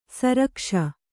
♪ sarakṣa